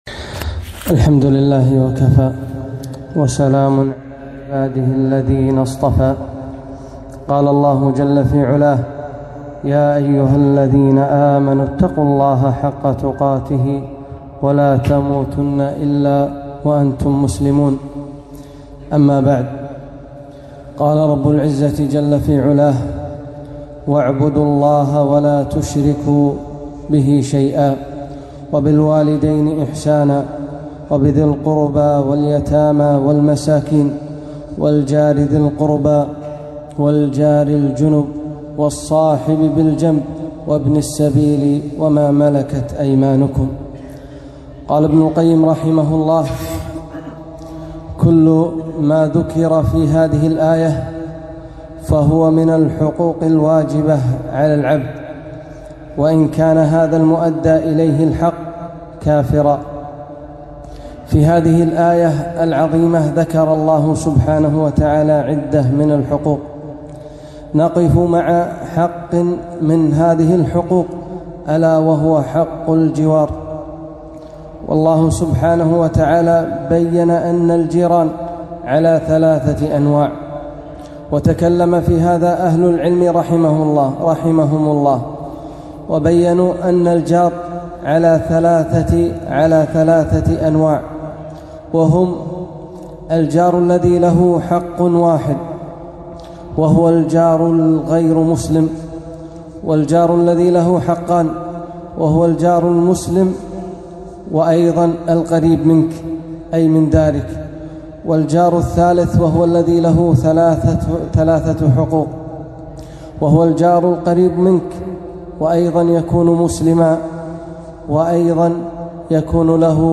خطبة - حقوق الأجار